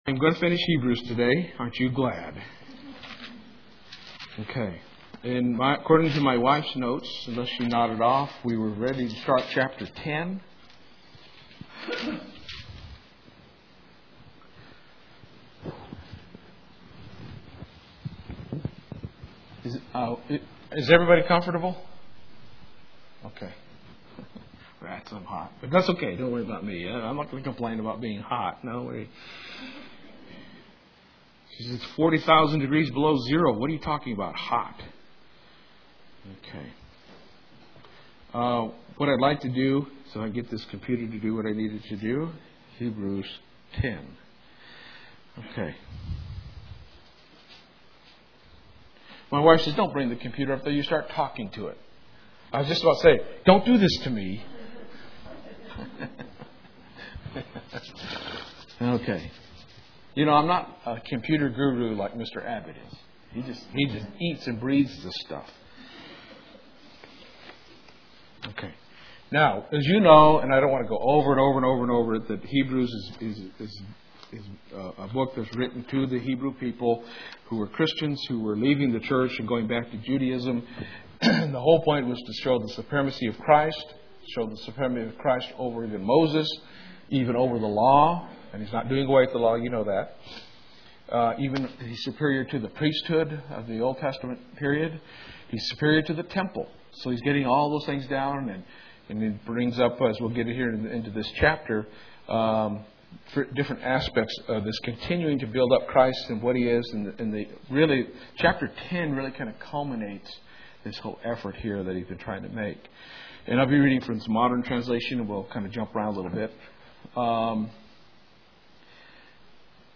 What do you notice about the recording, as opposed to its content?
Given in Lubbock, TX